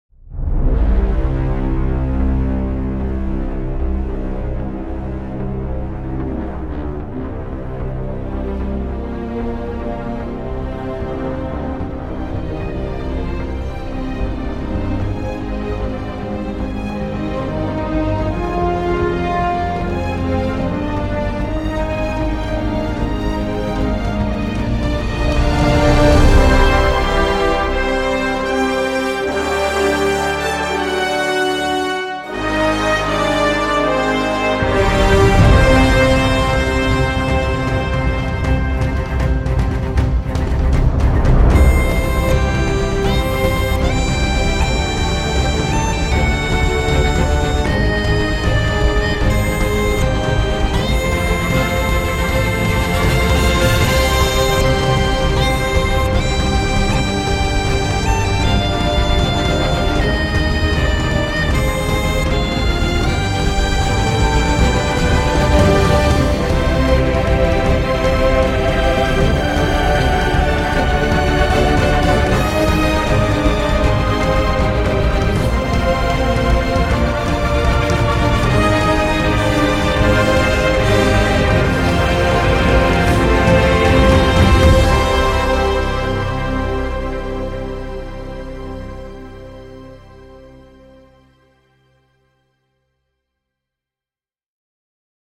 Ça sent le donjon creux et le dragon de synthèse